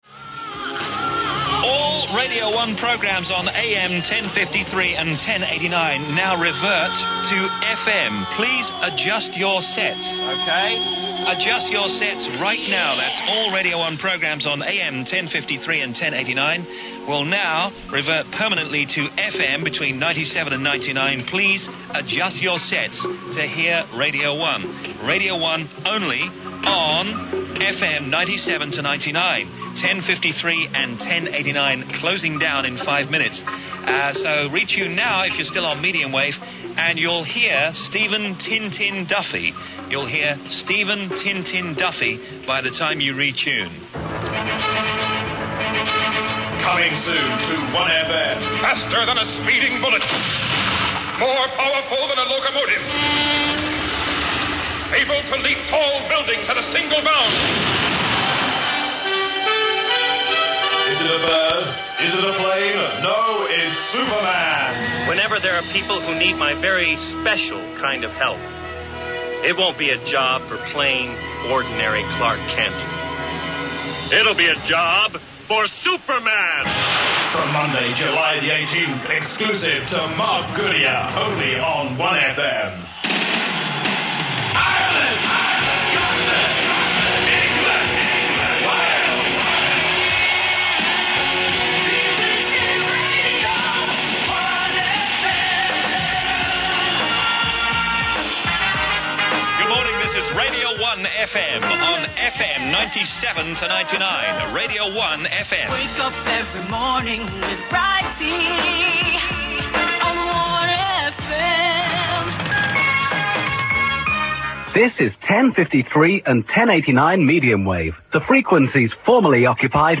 Enjoy here the final moments of Radio 1 on AM, as Steve Wright does that one last shove to the waveband knob; and Mark Goodier’s plantive cry for those who woke up a little late to the change.